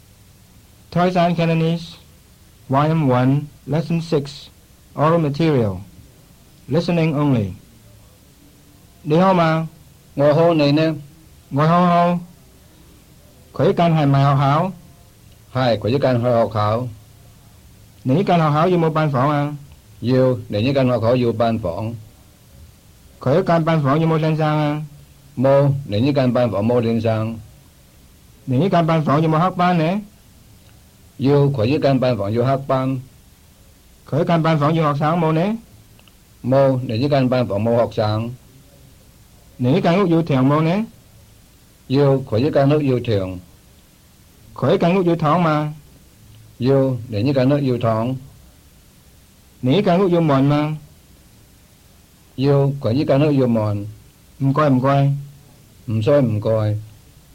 Oral Material (